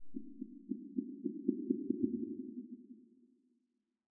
Minecraft Version Minecraft Version snapshot Latest Release | Latest Snapshot snapshot / assets / minecraft / sounds / block / creaking_heart / hurt / trail3.ogg Compare With Compare With Latest Release | Latest Snapshot